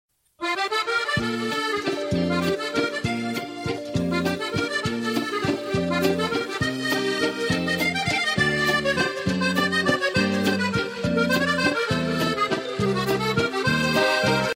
French Music